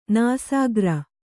♪ nāsāgra